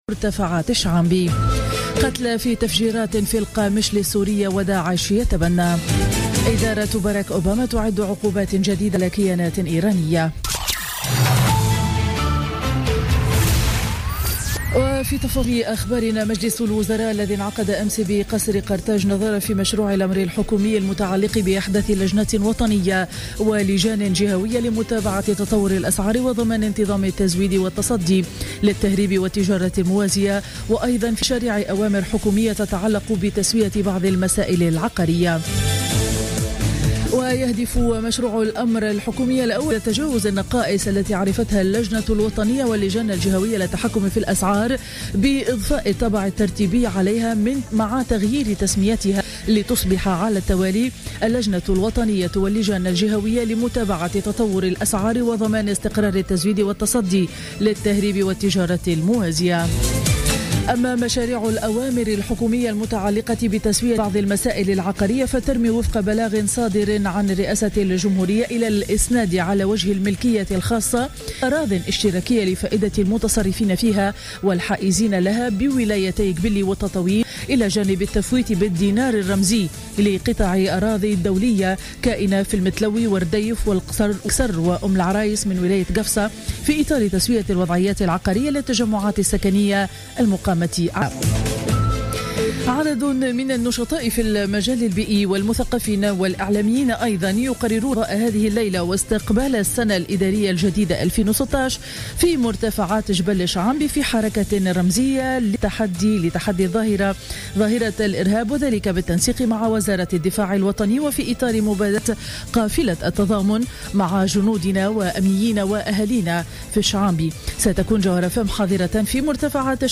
نشرة أخبار السابعة صباحا ليوم الخميس 31 ديسمبر 2015